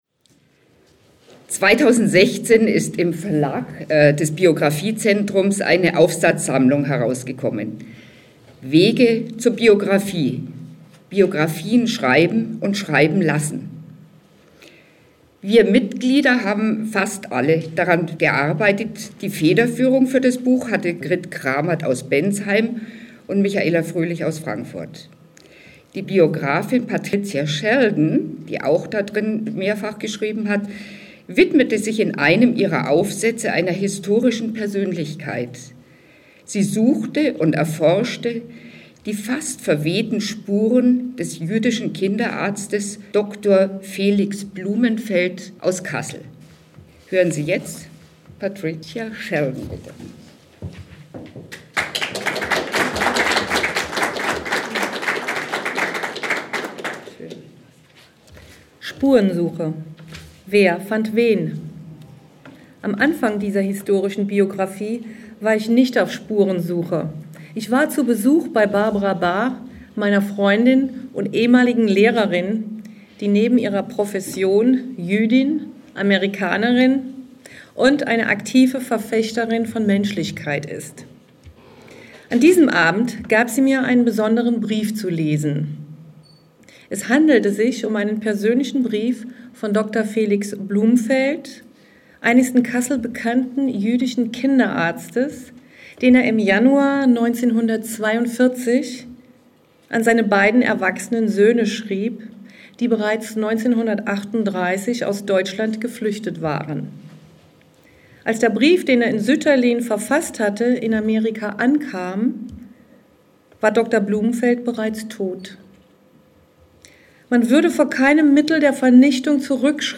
Lesung in Frankfurt